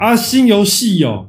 Tap and play instantly — free meme sound on Sound Buttons Hub.
Tags: voice, sound-effect